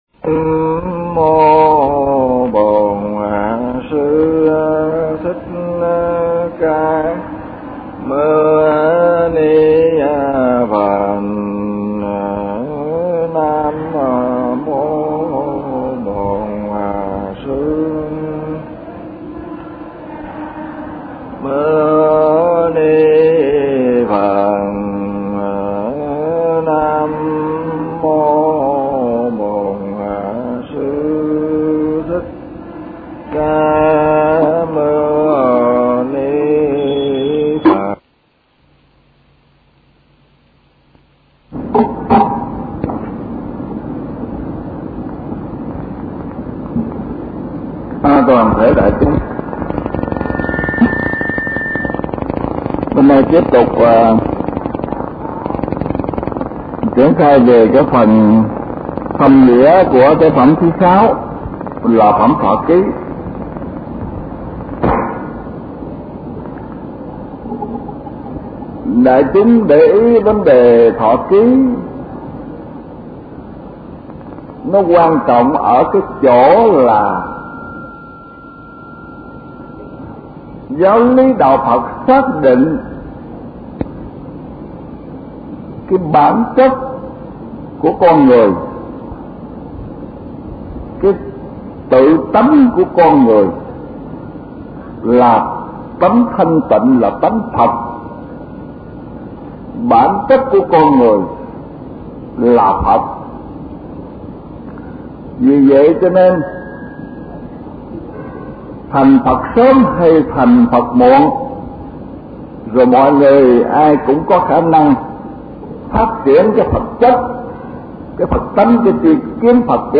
Kinh Giảng Kinh Pháp Hoa